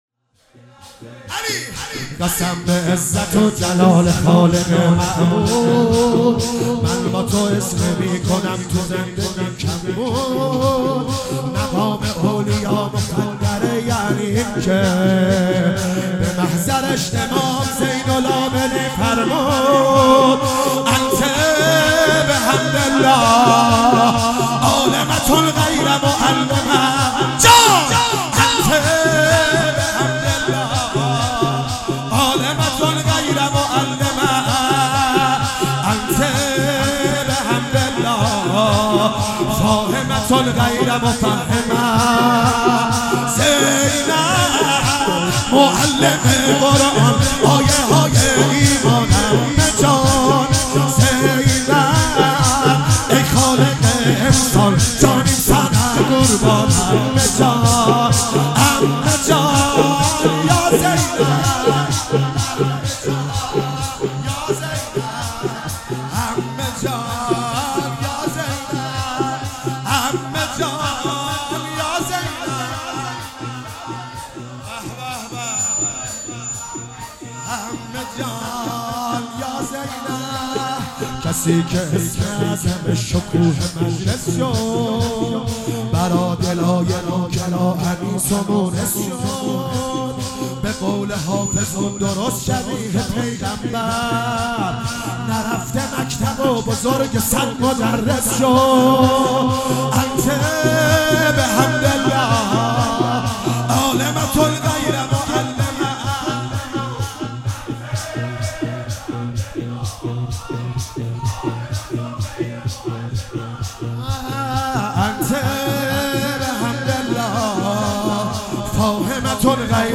هفتگی 1 دی 96 - شور - قسم به عزت و جلال معبود